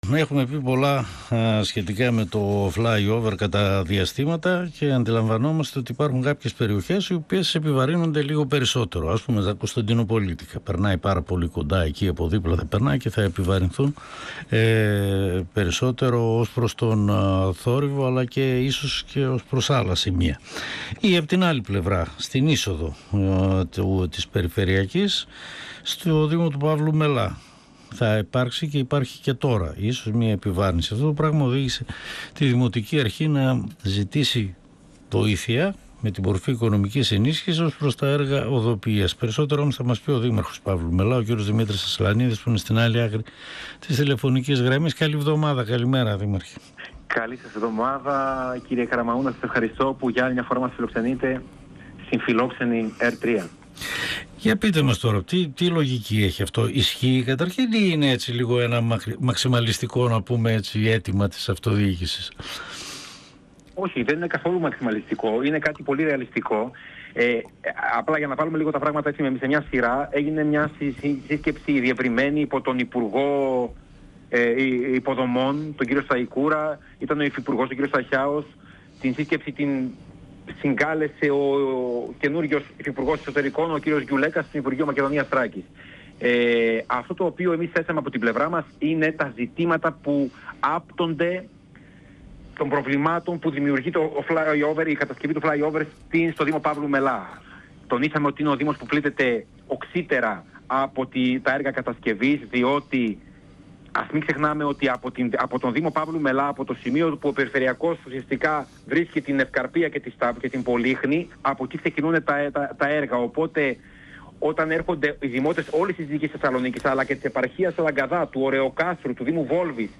Στο Αίτημα του Δήμου Παύλου Μελά για την οικονομική ενίσχυση της οδοποιίας σε οδικούς του άξονες, μεγάλης επιβάρυνσης από βαρέα οχήματα, λόγω των έργων κατασκευής της υπερυψωμένης λεωφόρου (fly over) αναφέρθηκε ο Δήμαρχος Παύλου Μελά Δημήτρης Ασλανίδης, μιλώντας στην εκπομπή «Εδώ και τώρα» του 102FM της ΕΡΤ3.